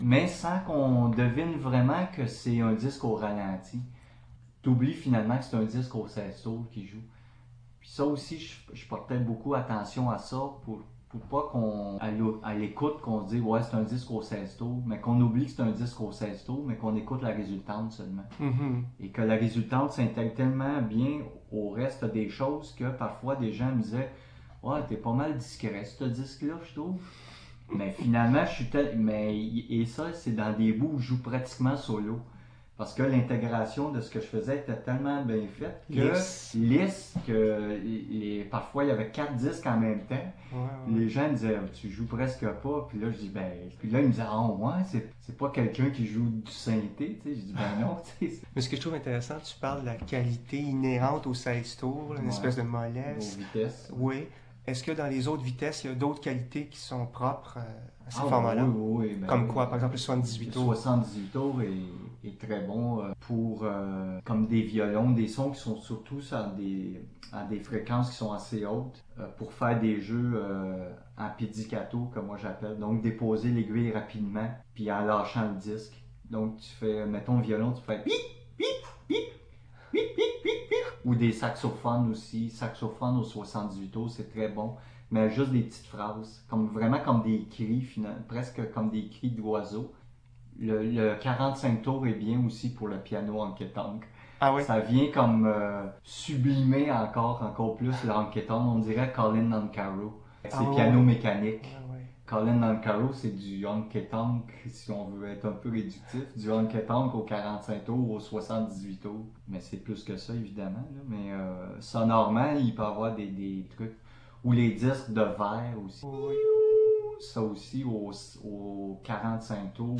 Résumé de l'entrevue :